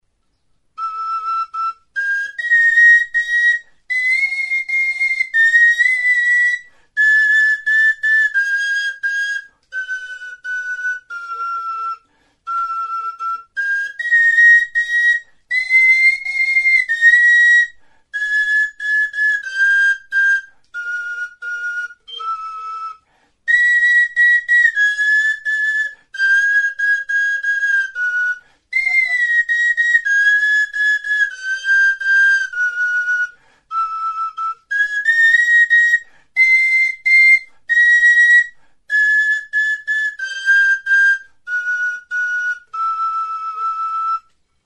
Aerófonos -> Flautas -> Embolo
Grabado con este instrumento.
TXULUBITA; pistoi flauta; Slide whistle
Plastikozko pistoi flauta da.